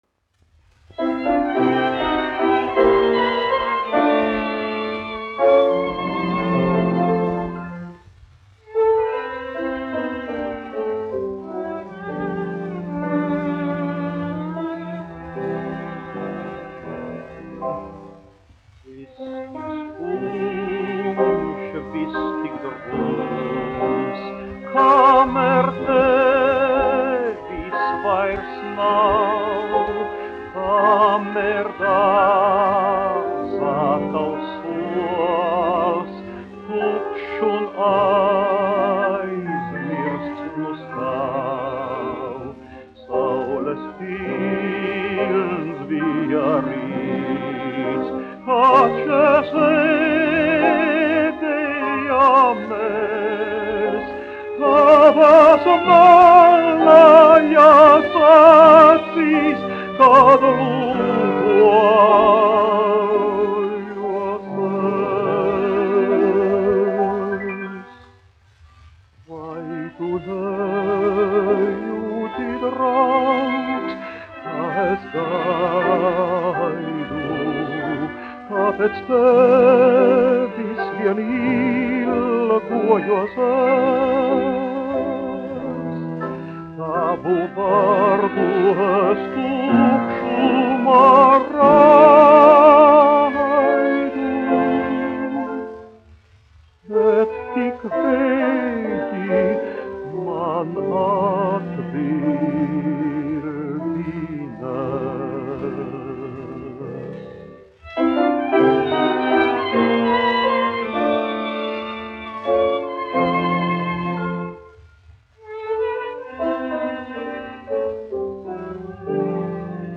1 skpl. : analogs, 78 apgr/min, mono ; 25 cm
Dziesmas (augsta balss) ar instrumentālu ansambli
Skaņuplate
Latvijas vēsturiskie šellaka skaņuplašu ieraksti (Kolekcija)